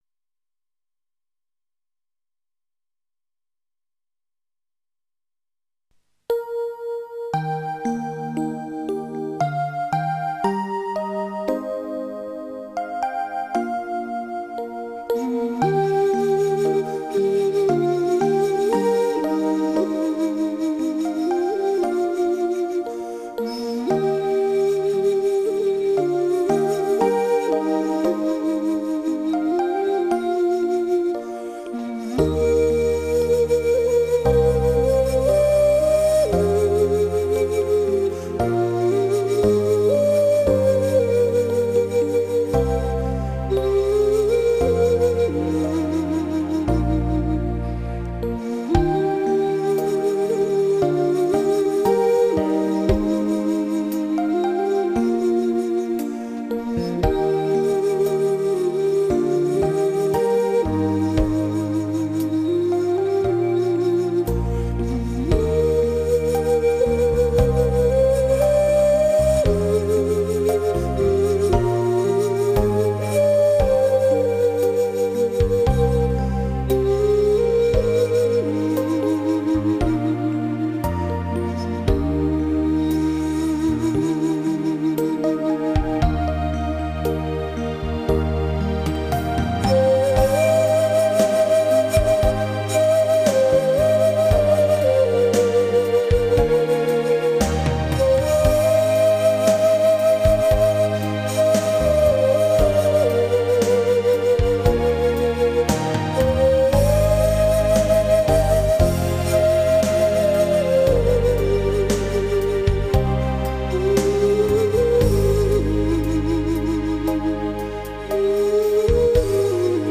埙的音色幽深、悲凄、哀婉、绵绵不绝，具有一种独特的音乐品质。
此专集14首音乐均为用最原始最古老的乐器来演绎欧美的流行音乐。